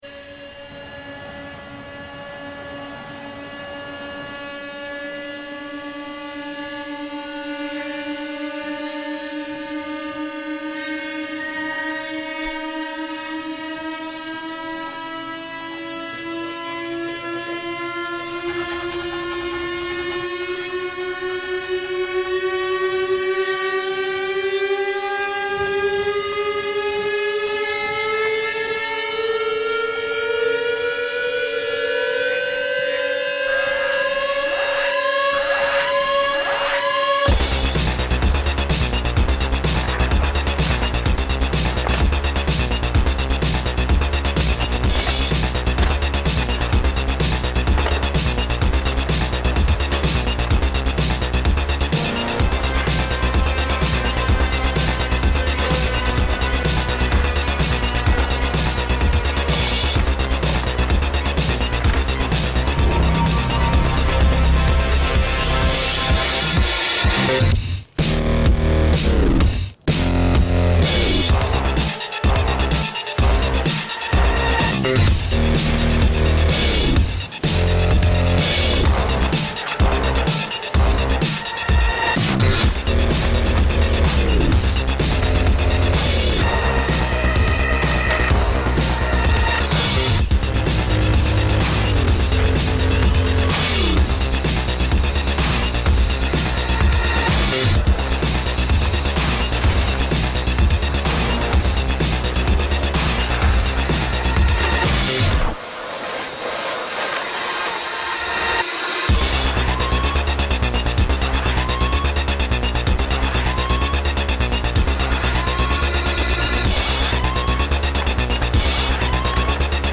banging french inspired electronic track